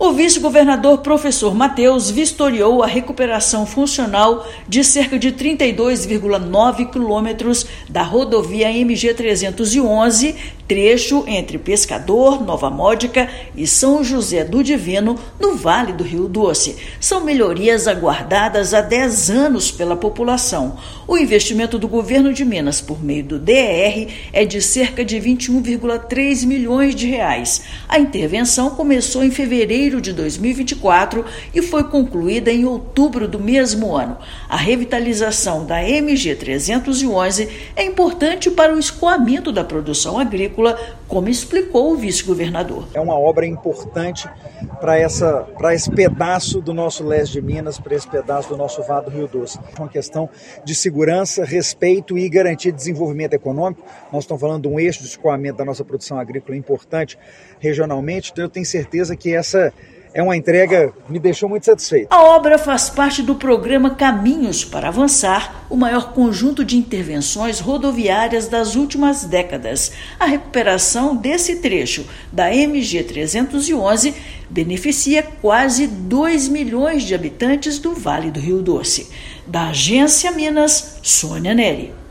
Revitalização de 33 quilômetros atende demanda da população, que esperou dez anos pelas obras, e beneficiará 1,7 milhão de habitantes da região. Ouça matéria de rádio.